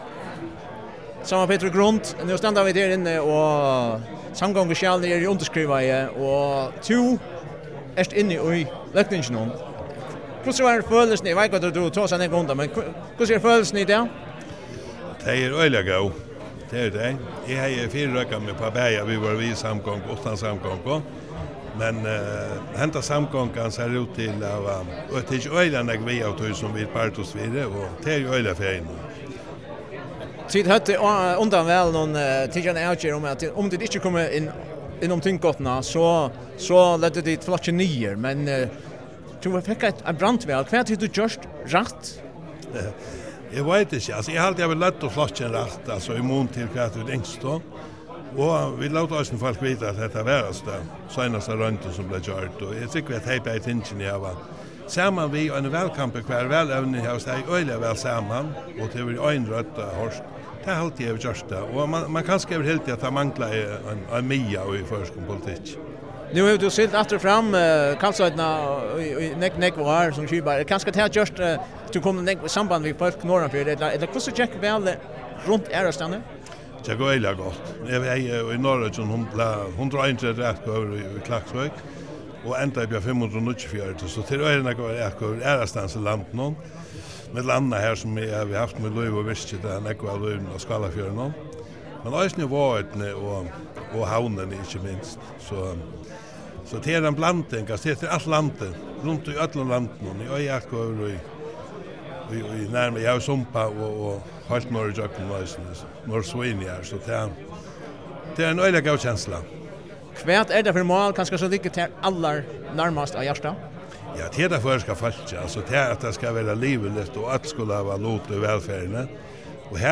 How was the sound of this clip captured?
Samrøðan bleiv gjørd eftir tíðindafundin í Løgtinginum, har samgonguskjalið bleiv undirskrivað.